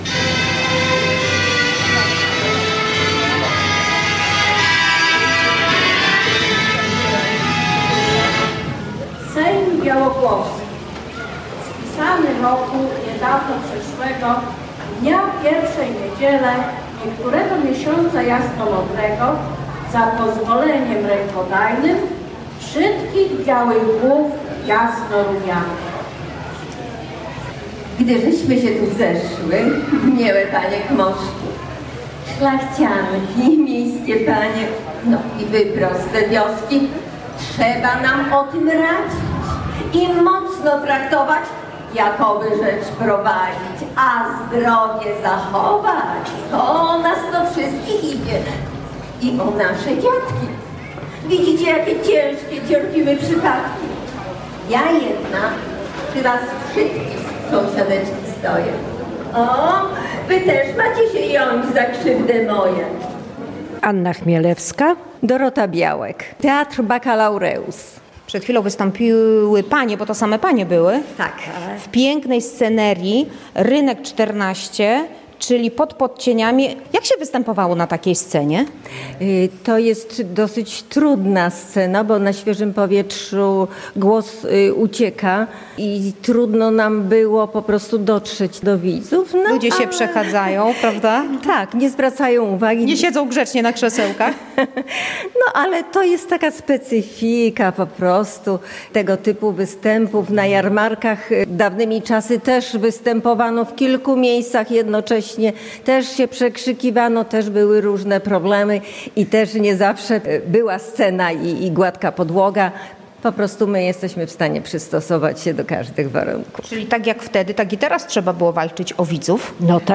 O tym co podczas Jarmarku działo się w świąteczny piątek w reportażu